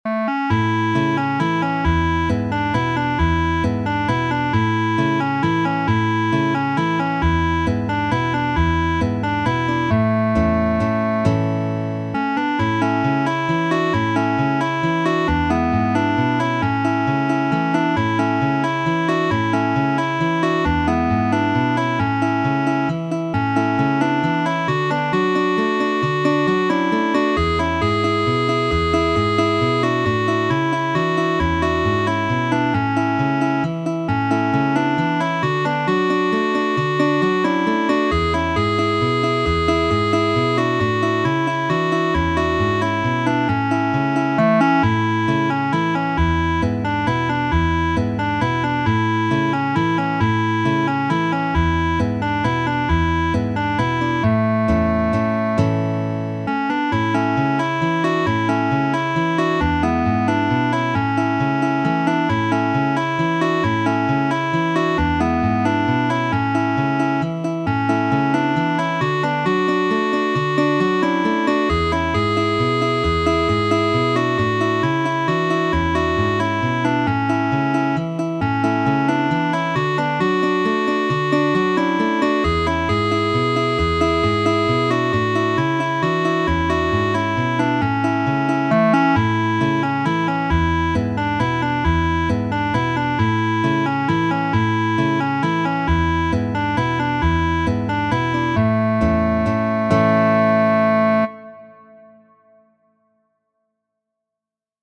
Muziko:
Kaŝita, popolkanto sudamerika, aranĝita de Majkel Dalavenu' por tri gitaroj kaj rearanĝita de mi por klarneto kaj gitaroj..